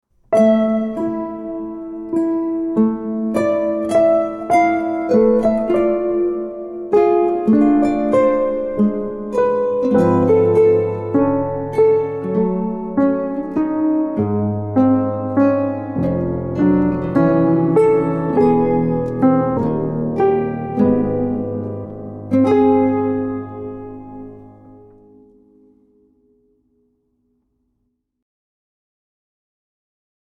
guitar
keyboard/composer.